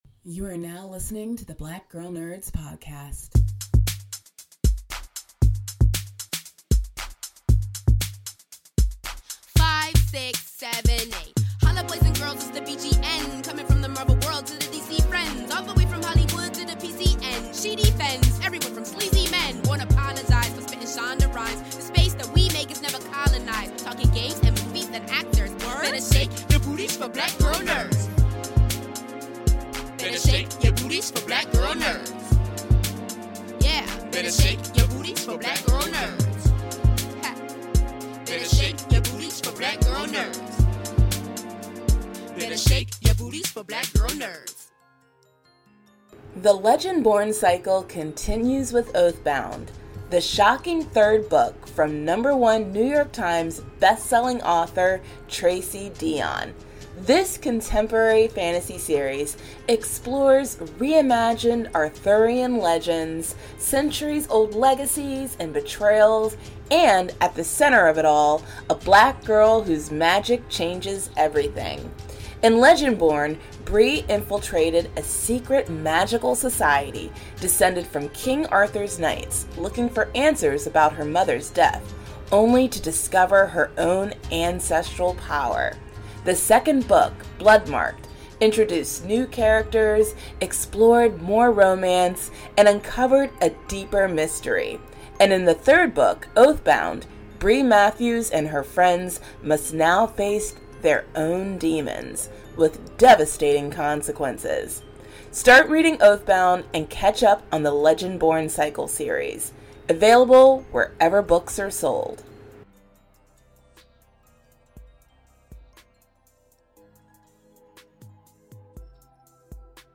This was recorded during the middle of festival, so not all films were screened at the time of the discussion.